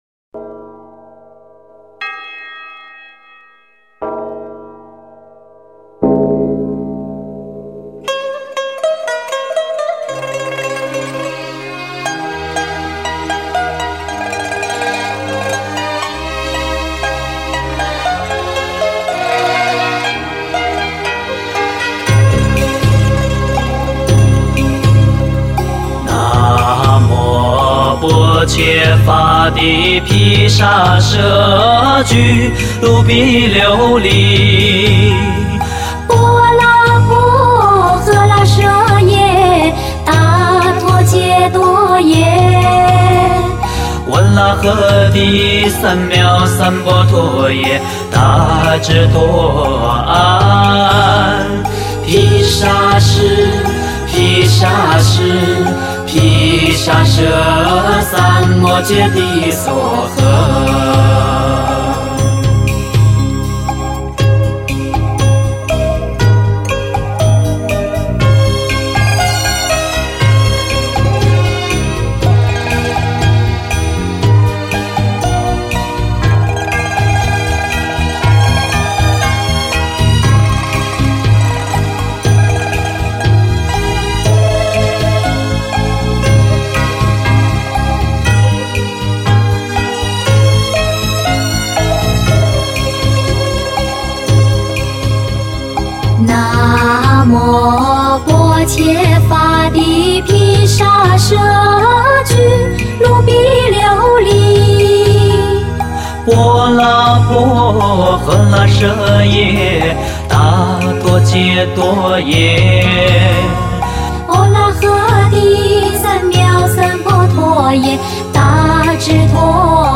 [10/4/2010]清脆平和的男女合唱：药师灌顶真言（并附：南无消灾延寿药师佛圣号）